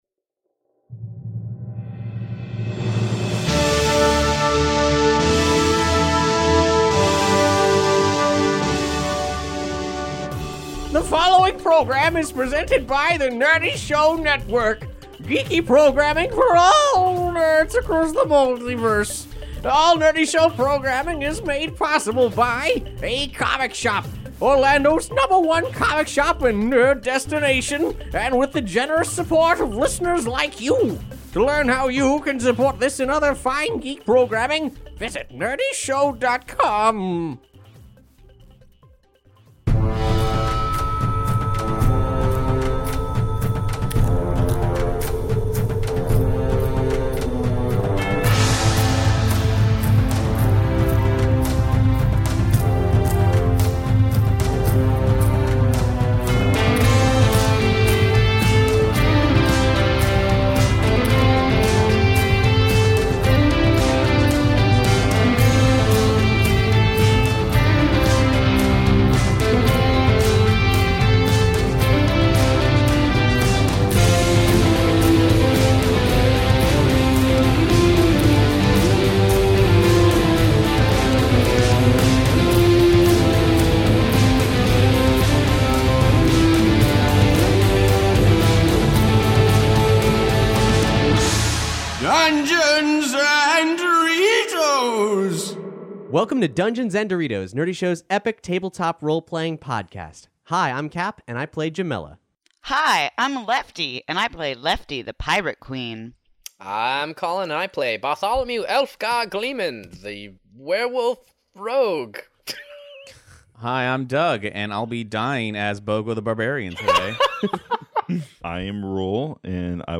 After all, we may be an audio drama, but the roleplaying is real, and so is the character death.